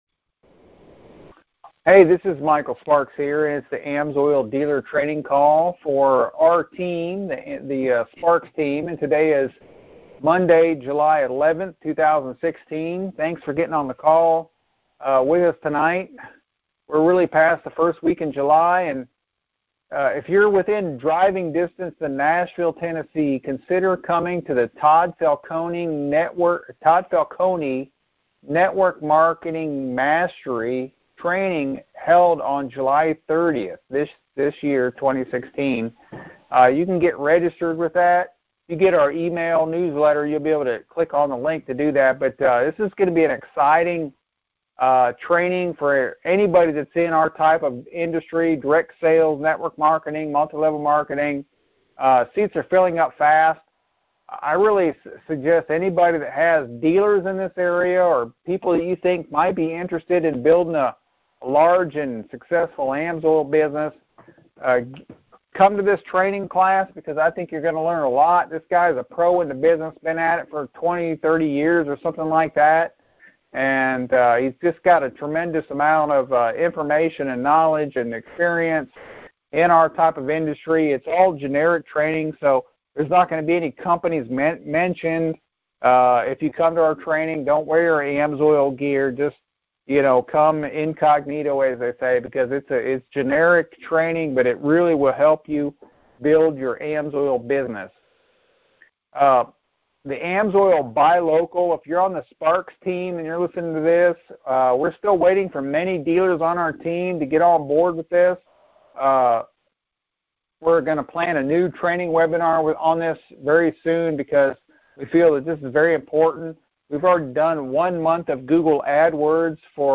Sparks Team AMSOIL Dealer Training Call |July 11th, 2016